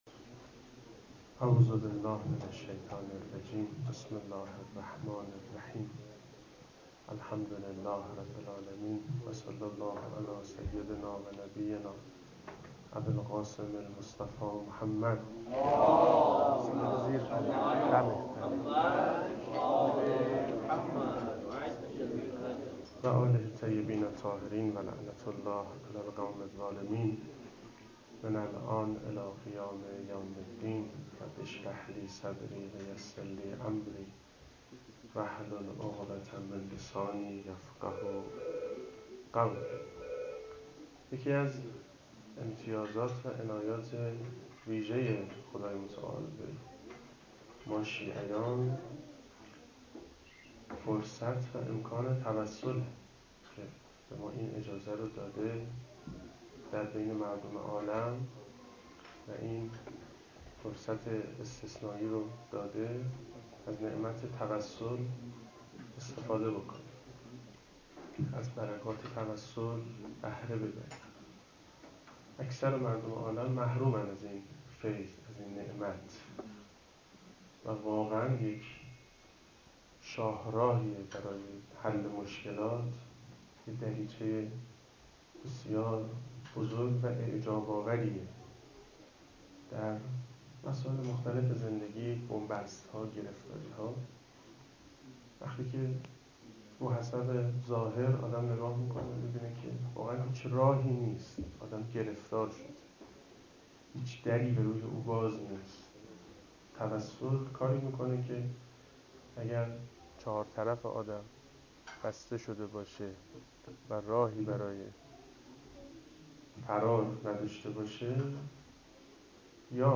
✔ این سخنرانی در فاطمیه ۱۳۹۷ در مشهد مقدس و در یک جلسه ایراد شده است.